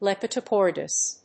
音節lep・i・dop・ter・ous 発音記号・読み方
/lèpədάptərəs(米国英語)/